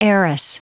Prononciation du mot heiress en anglais (fichier audio)
Prononciation du mot : heiress